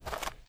STEPS Dirt, Walk 03.wav